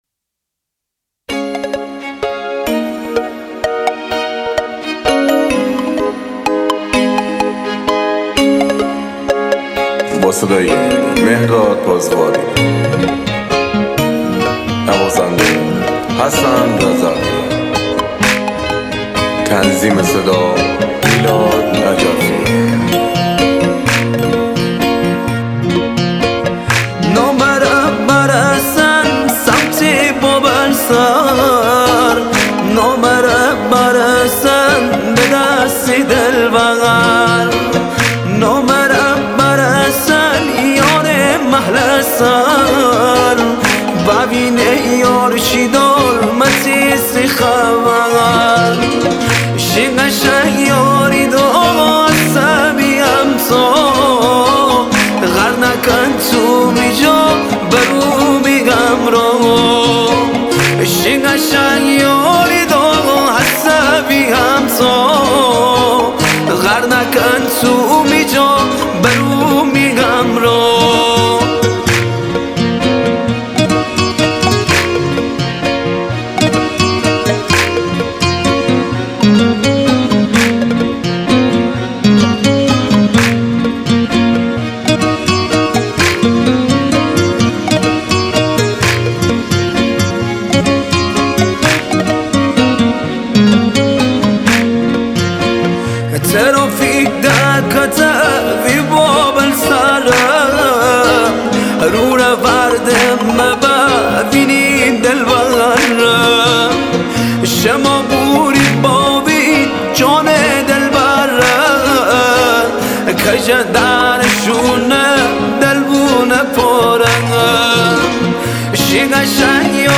آهنگ شمالی
آهنگ گیلانی
آهنگ های شاد شمالی